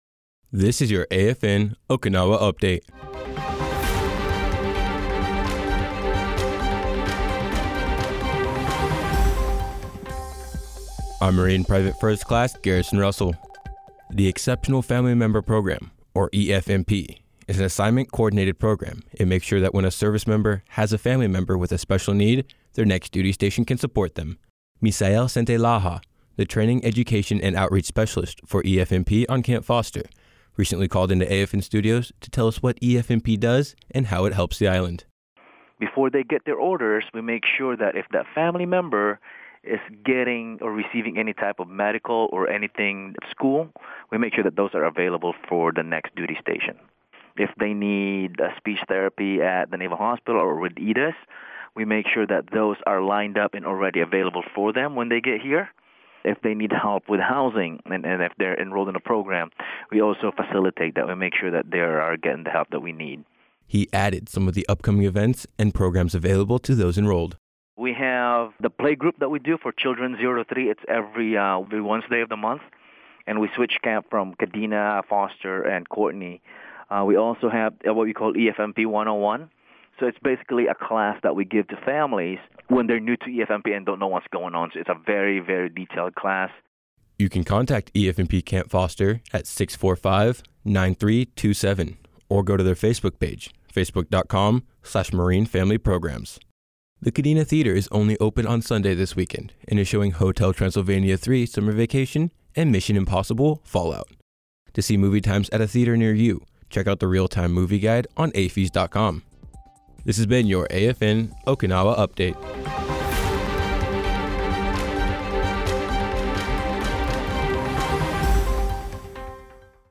180727-Radio Newscast